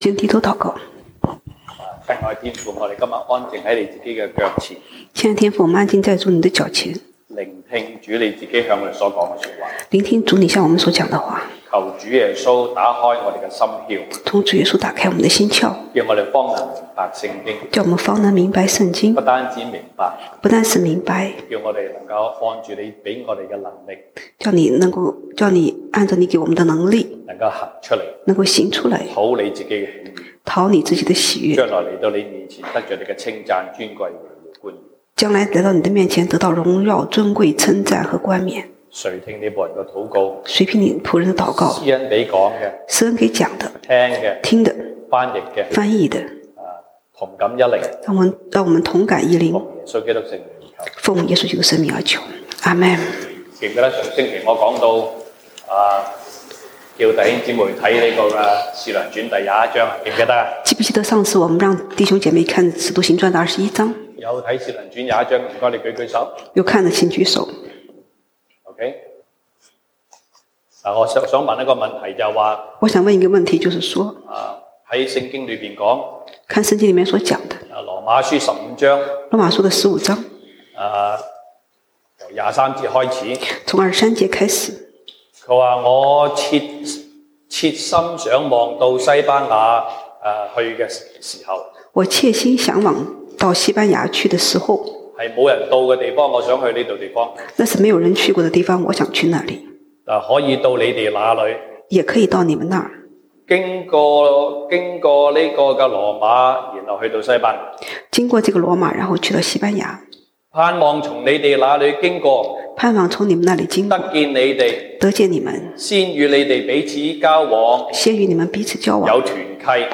西堂證道(粵語/國語) Sunday Service Chinese: 主若願意、我們就可以活著、也可以作這事、或作那事。